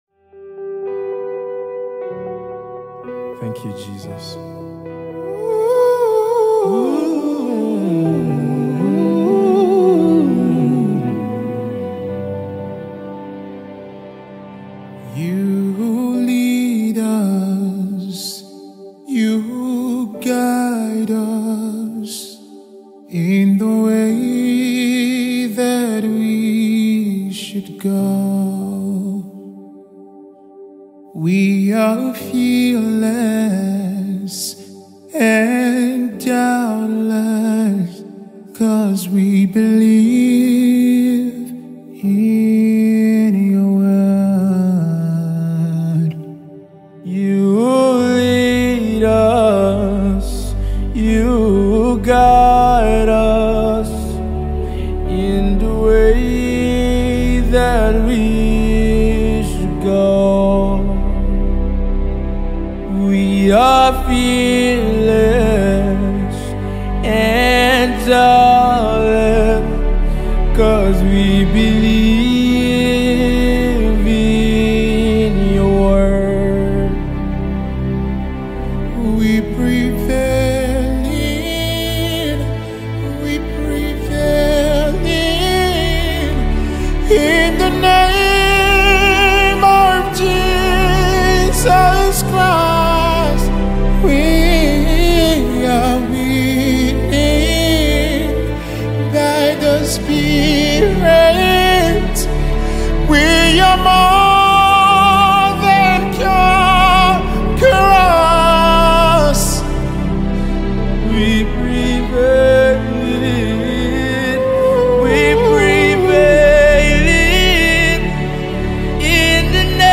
inspirational gospel song
spirit-filled worship song